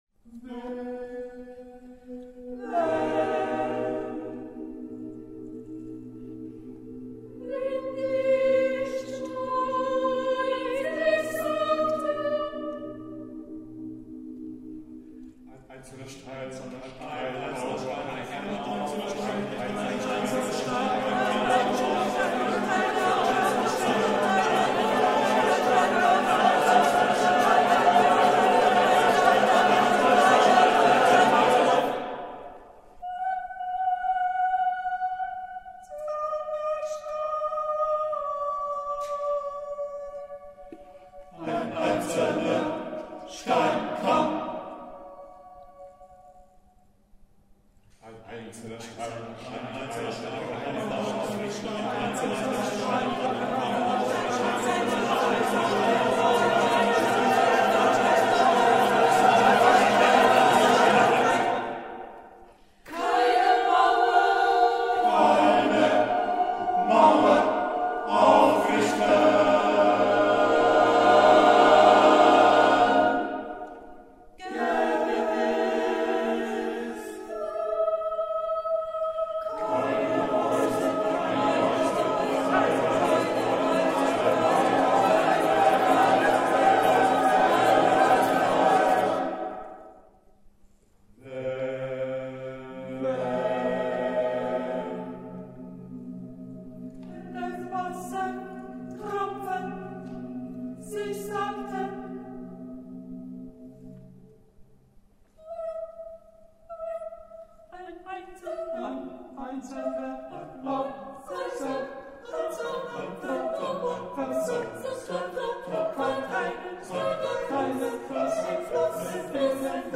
six choral works